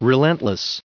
645_relentless.ogg